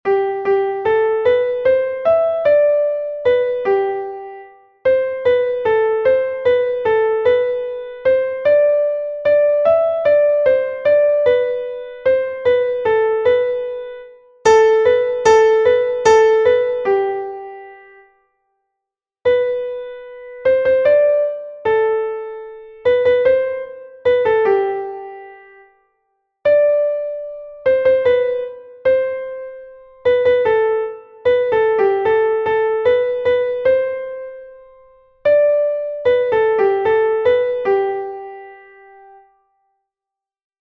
Exercise 4.  There are some time signature changes: 6/8 + 3/4 + 2/4 and these rhythmic patterns: dotted crotchet + two semiquavers; two semiquavers + a quaver slurred to a crotchet; and triplets.
Rhythmic reading 3